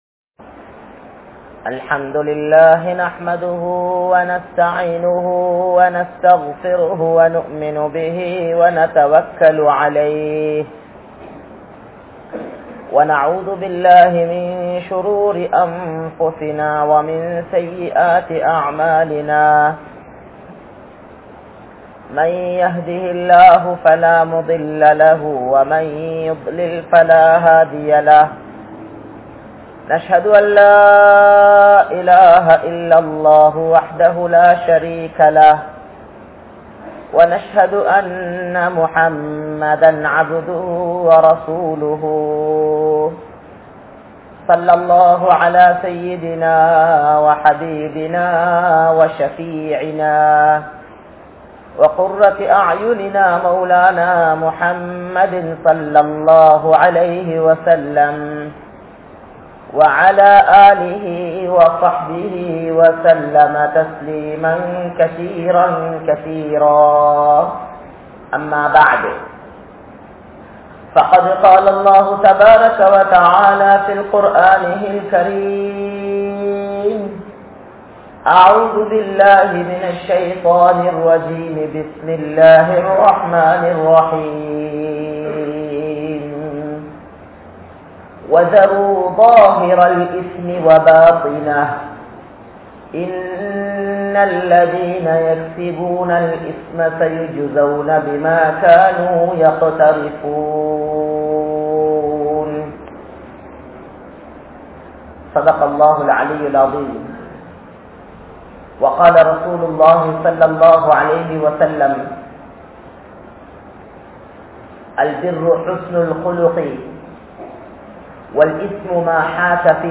Allah`vin Anpu (அல்லாஹ்வின் அன்பு) | Audio Bayans | All Ceylon Muslim Youth Community | Addalaichenai
Muhiyadeen Jumua Masjith